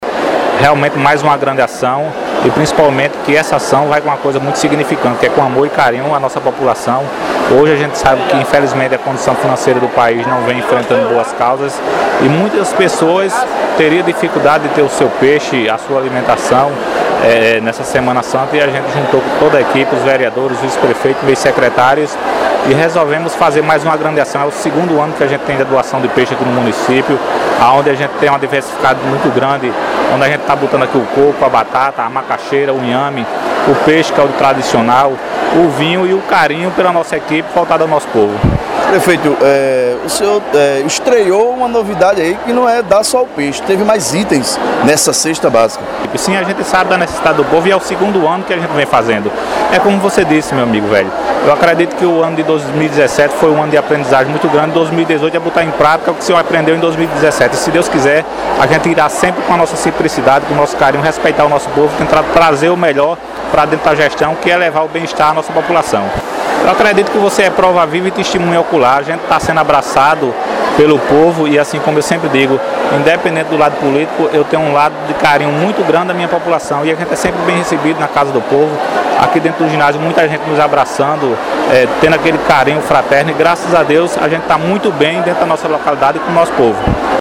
Ouça áudio do prefeito Tiago Lisboa:
Tiago-prefeito.mp3